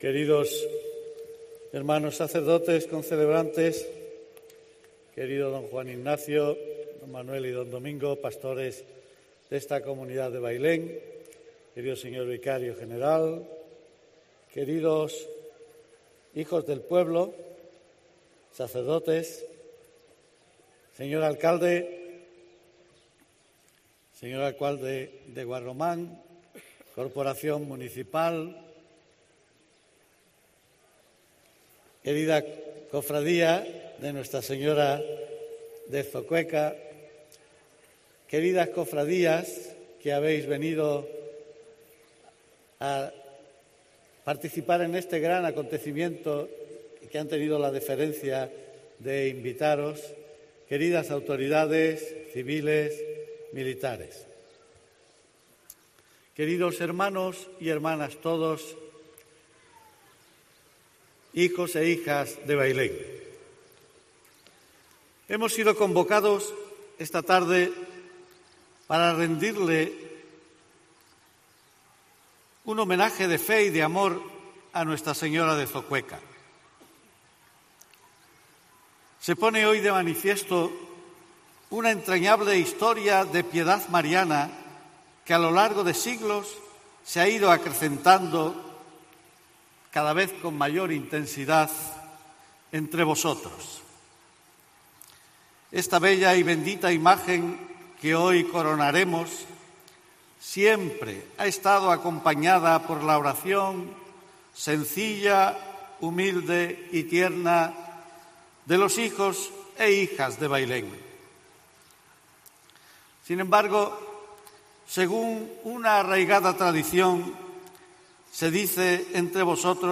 La Eucaristía de coronación, presidida por el Obispo diocesano, Don Amadeo Rodríguez Magro, y concelebrada por más de una treintena de sacerdotes...
Homilía del Obispo de Jaén en Bailén